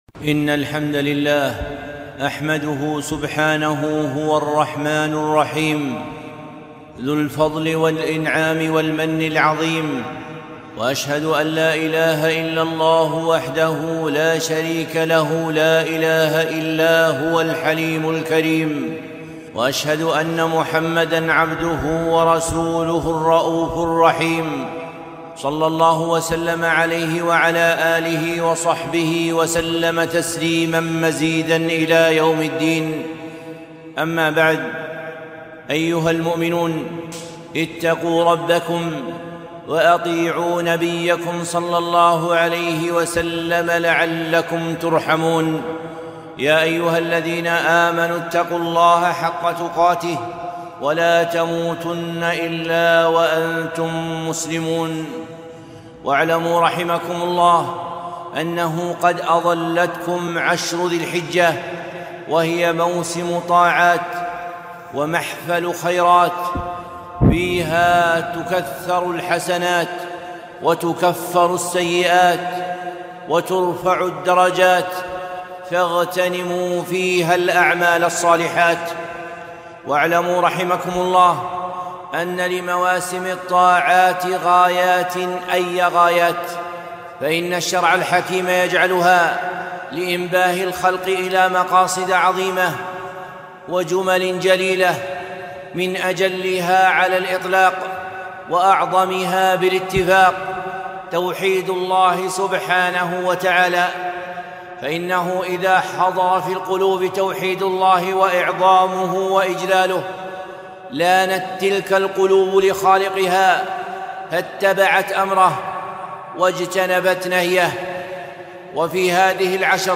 خطبة - مشاهد التوحيد في عشر ذي الحجة